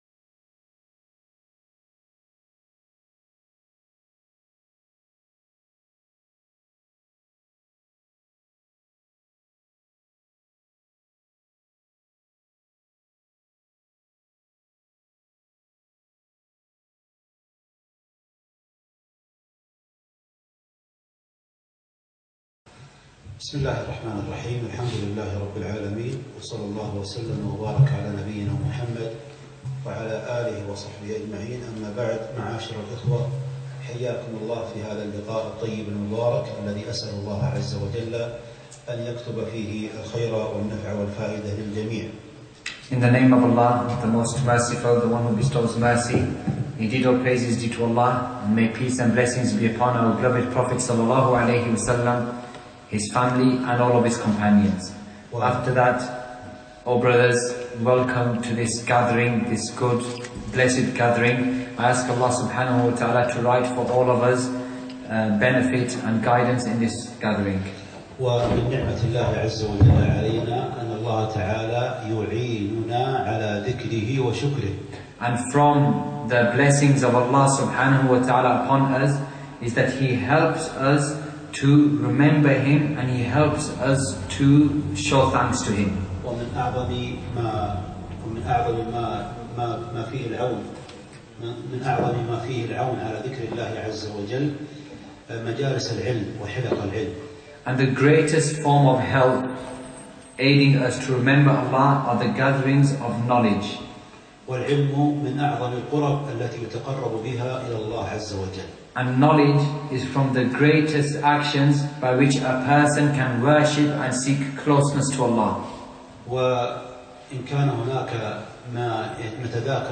محاضرة أهمية العلم - مترجم انجليزي